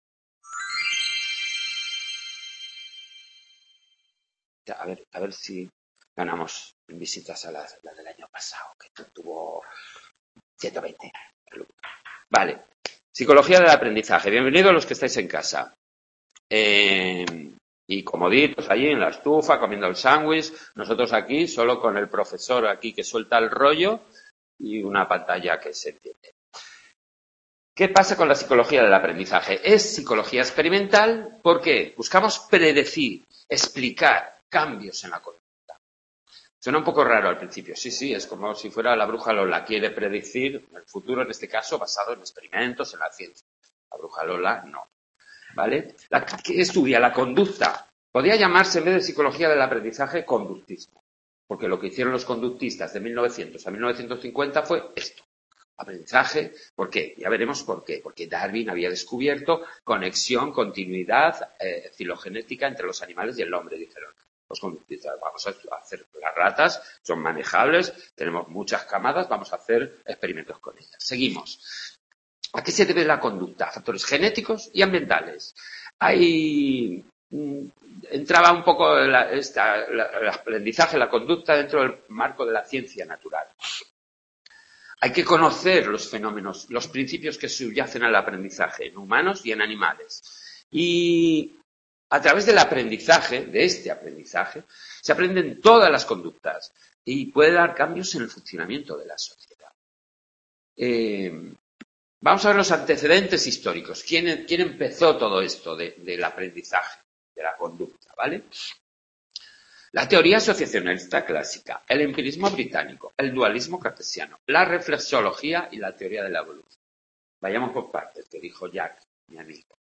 Grabado en el Centro Asociado de Sant Boi